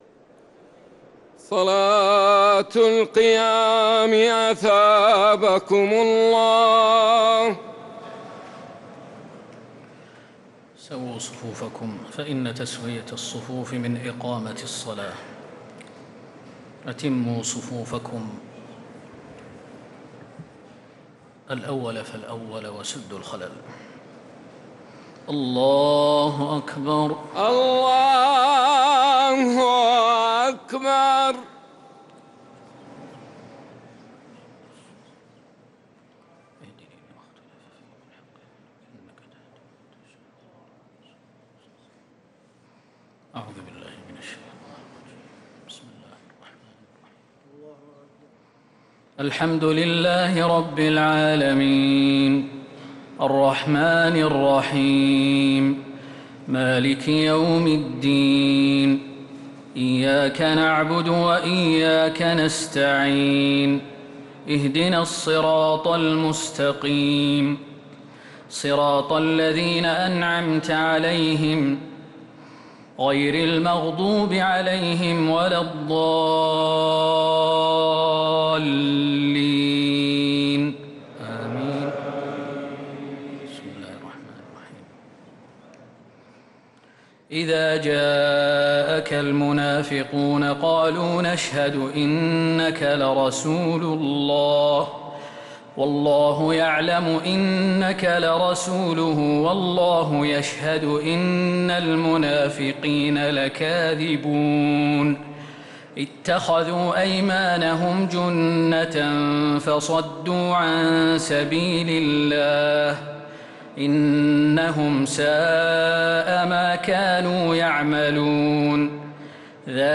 تراويح ليلة 28 رمضان 1446هـ من سورة المنافقون إلى سورة الملك | Taraweeh 28th night Ramadan 1446H Surah Al-Munafiqoon to Al-Mulk > تراويح الحرم النبوي عام 1446 🕌 > التراويح - تلاوات الحرمين